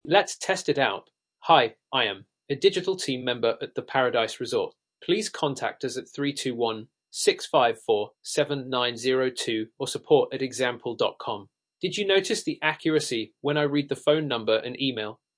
Standard Voices for Realtime streaming
British
masculine, positive, comfortable, polite